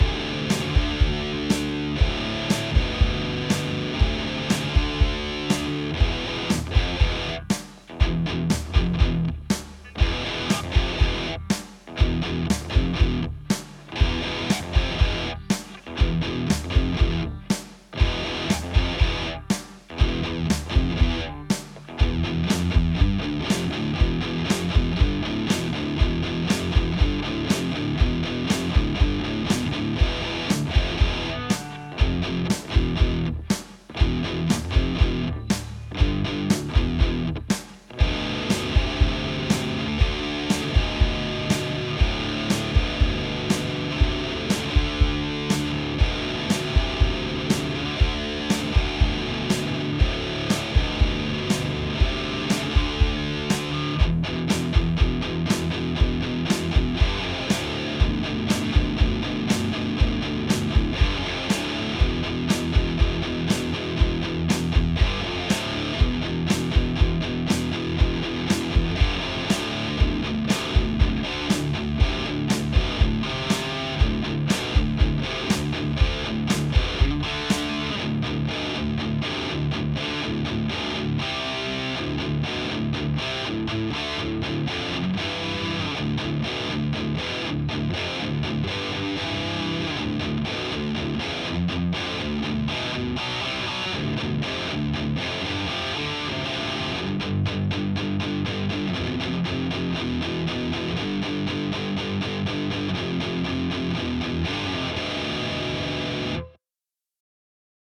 вот так пишет аудио Apollo DUO USB/ , качество подходит ?